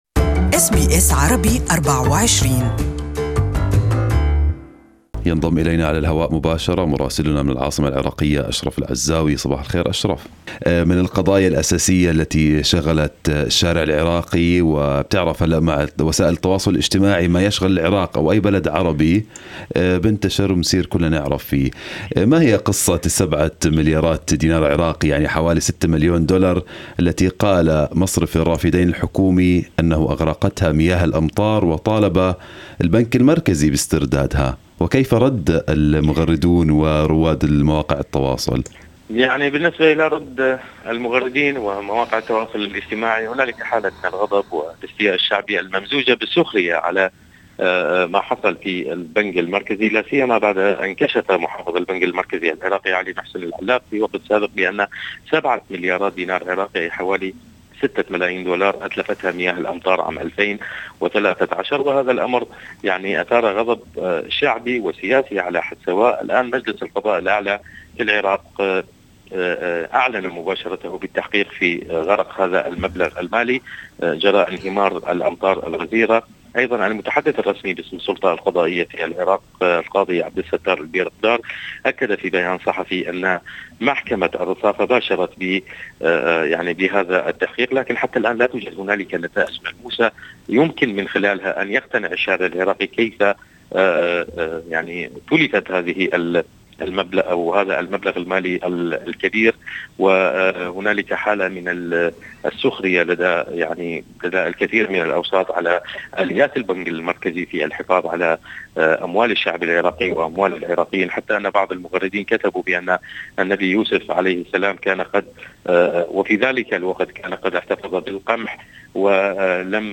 our correspondent in Baghdad has the details